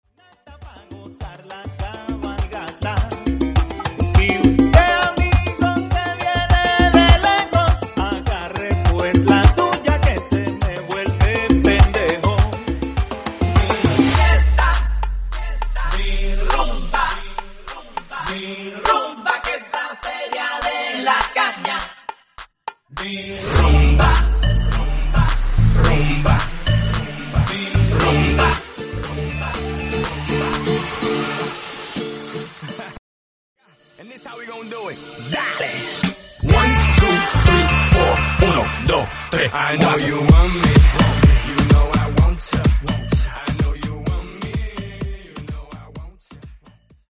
Electro Pop , Salsa , Transición